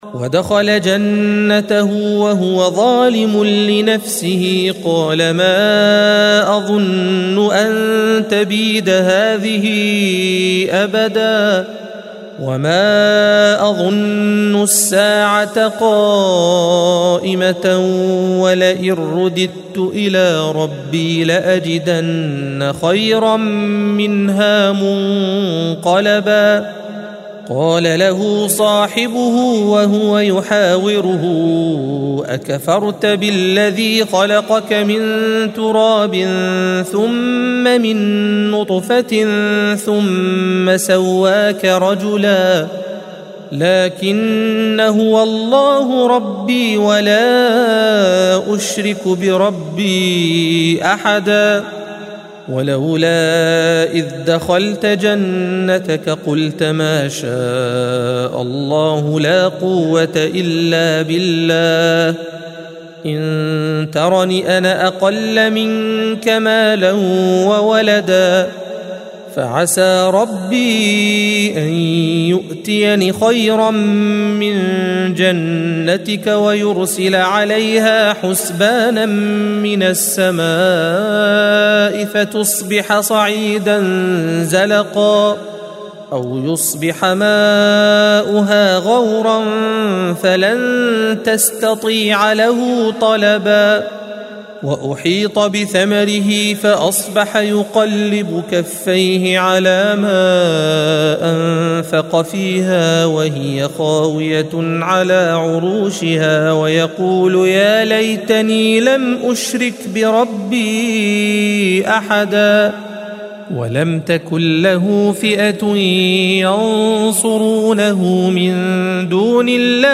الصفحة 298 - القارئ